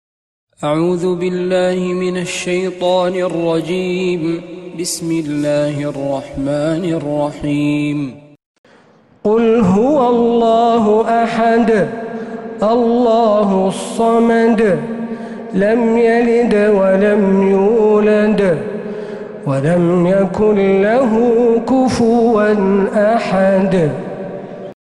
سورة الإخلاص من تراويح الحرم النبوي